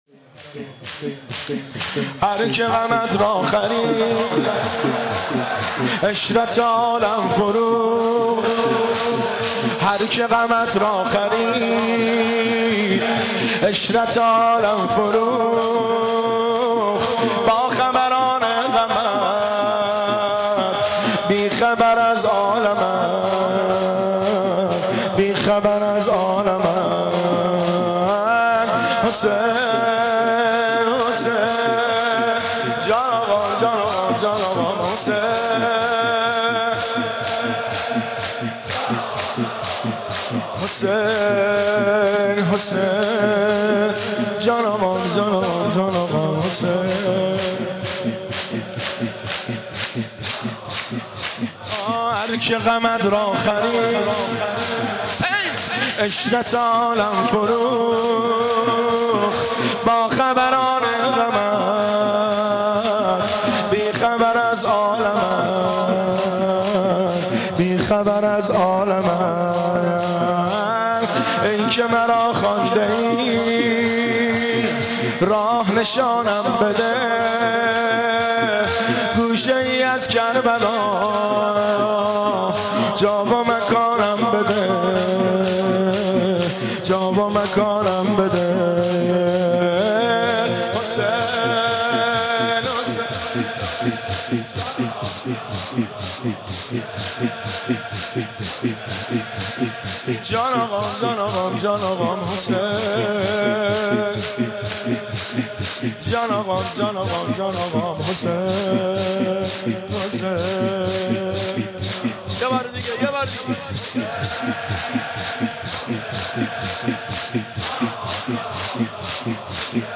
در هیئت بین الحرمین تهران اجرا شده است
خسته و بی یار در دل غم ها ( زمینه )